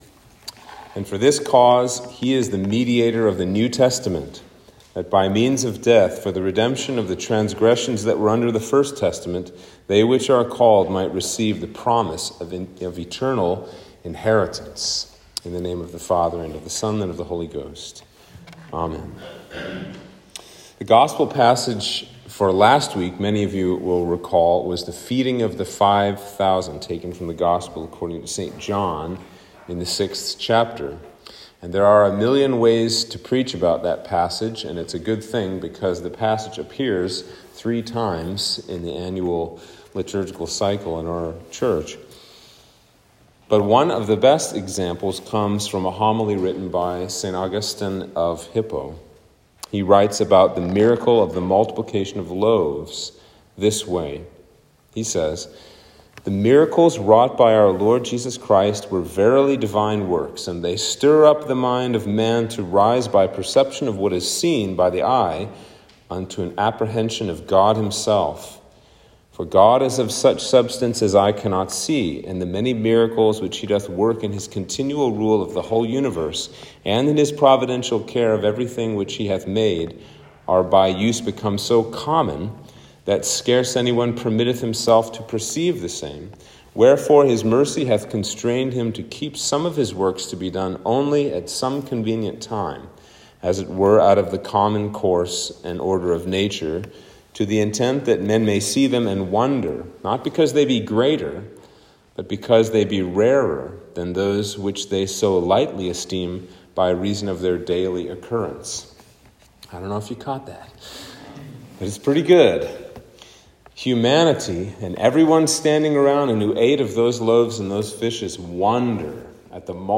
Sermon for Passion Sunday (Lent 5)